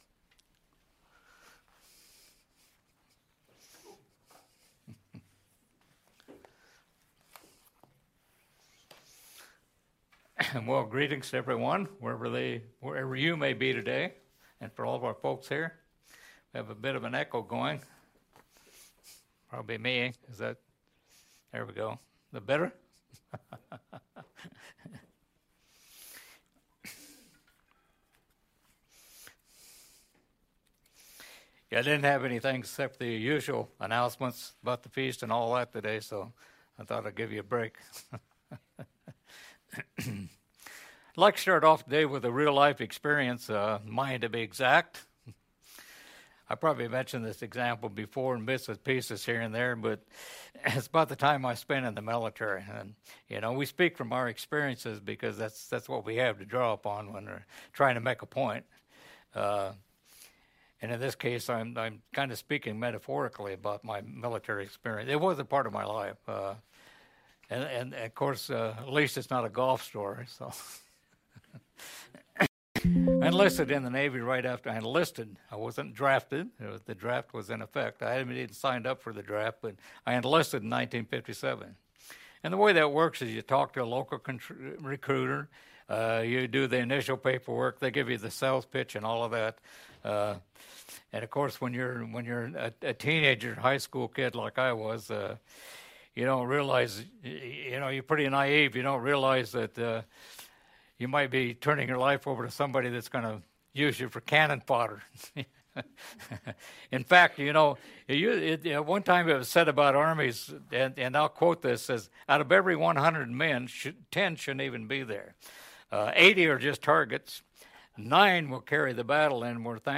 New Sermon | PacificCoG